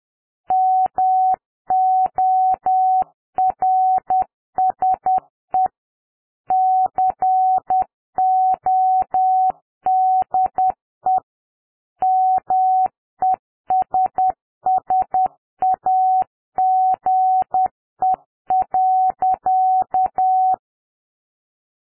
Morse code audio generation tool
$ echo "Morse code message." |python3 play.py -f 750 --wpm 10